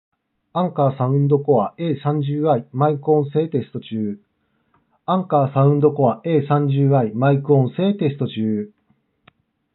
マイク性能は「Anker Soundcore A30i」と同程度
値段相応のマイク性能。高いわけではないが低いわけでもありません。普通に聞けるレベルです。